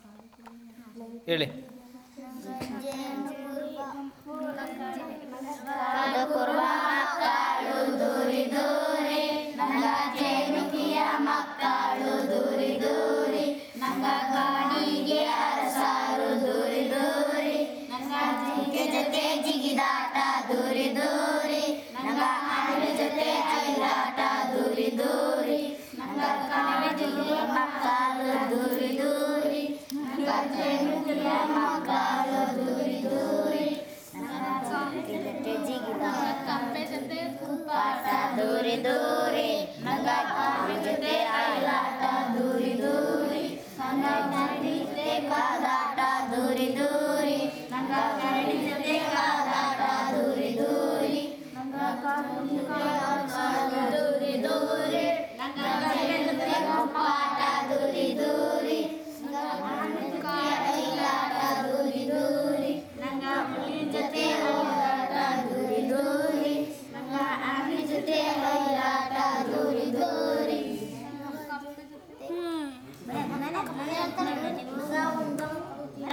Performance of folksong by Jenu Kuruba children